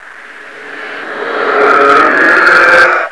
deadsignal5.wav